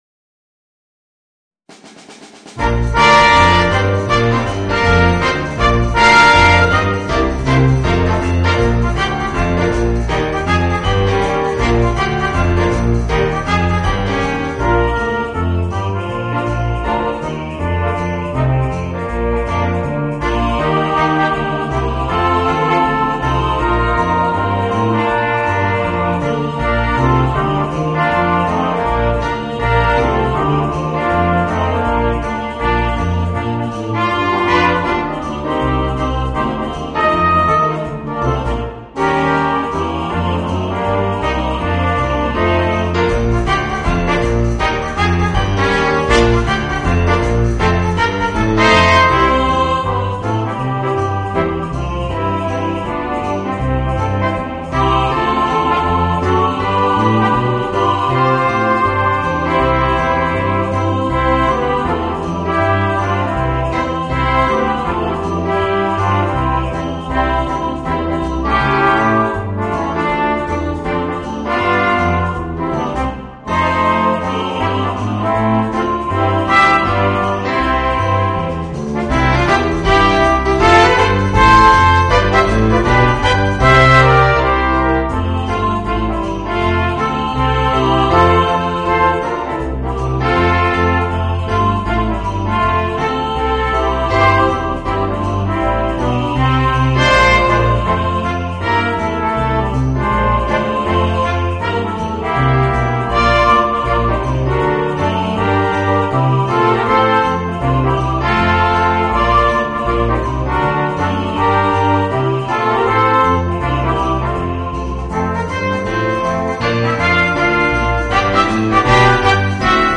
Voicing: 2 Trumpets, 2 Trombones and Chorus